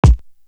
Germanic Kick.wav